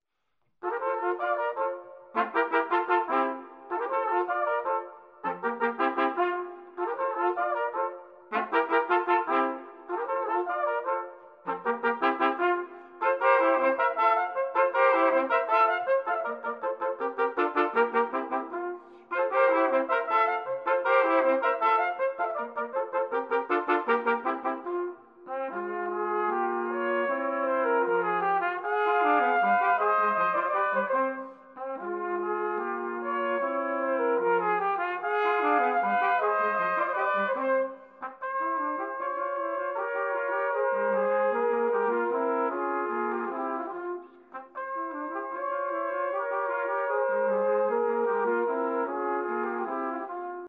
Classique